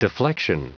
Prononciation du mot deflection en anglais (fichier audio)
Prononciation du mot : deflection